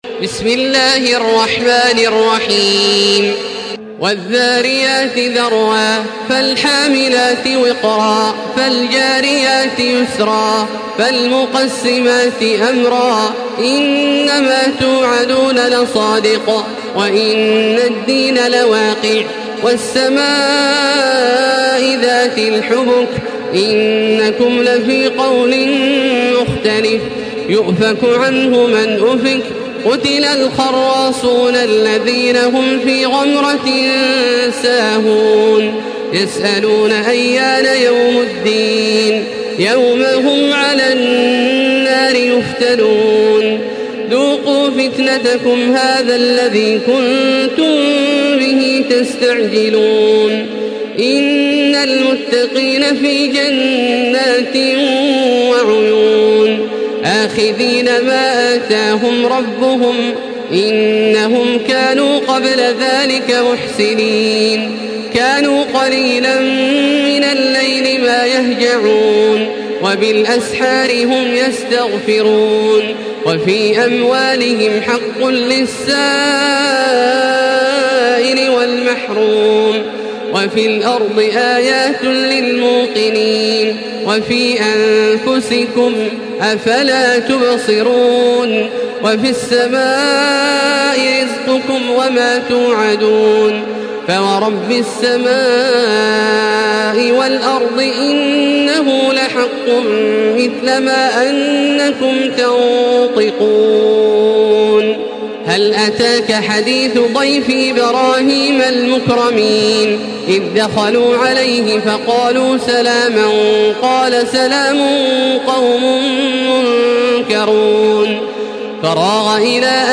Makkah Taraweeh 1435
Murattal